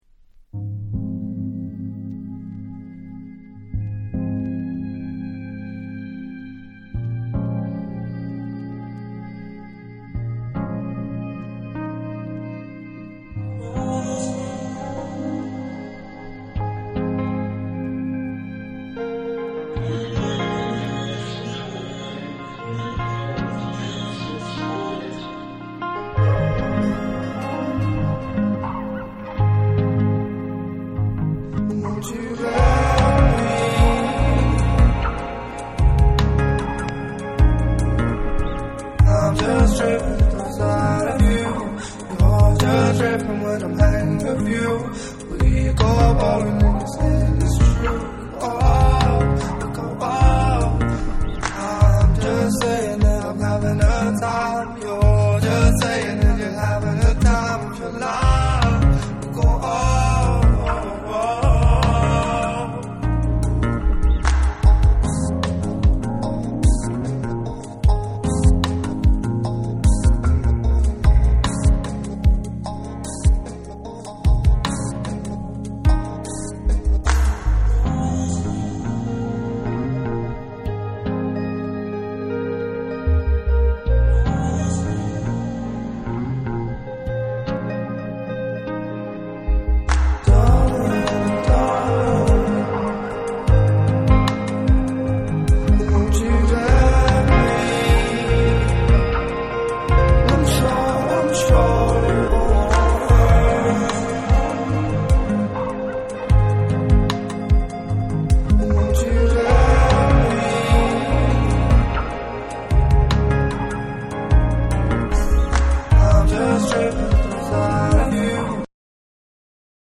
BREAKBEATS / CHILL OUT